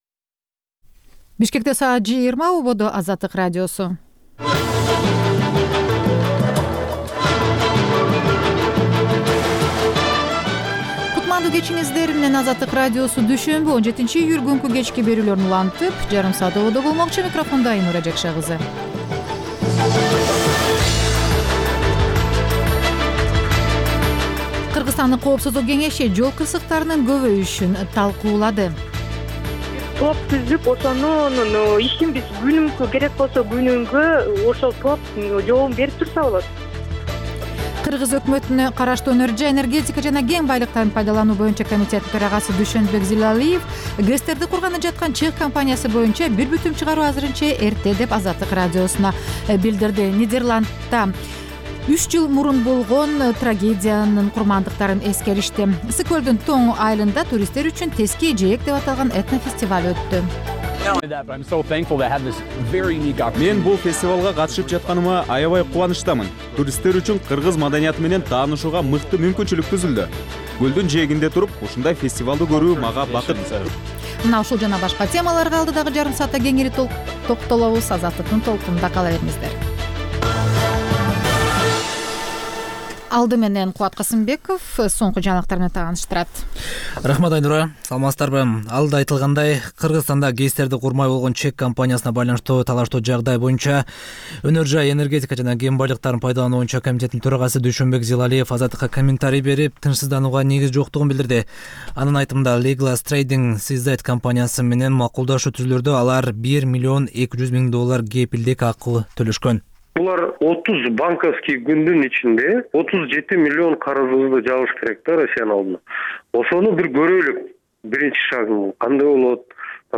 Бул үналгы берүү ар күнү Бишкек убакыты боюнча саат 20:00дан 21:00гө чейин обого түз чыгат.